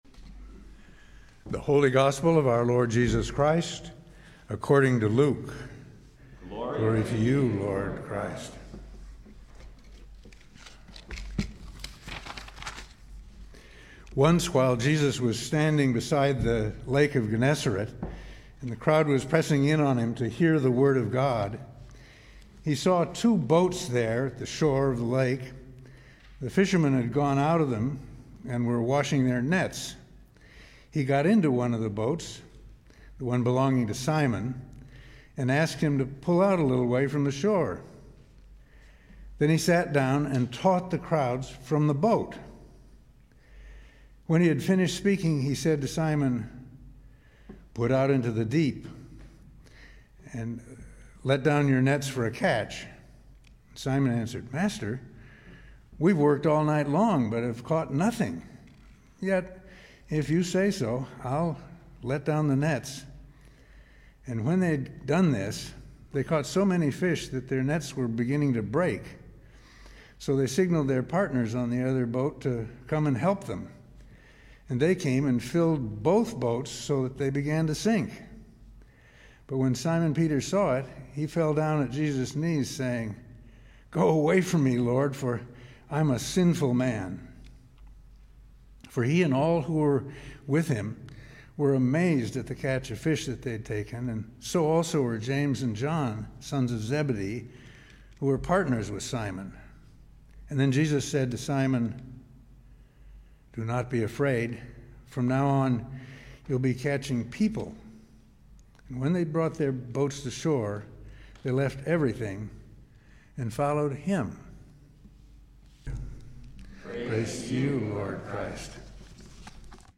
Gospel & Sermon, February 6, 2022 - St. Andrew's Episcopal Church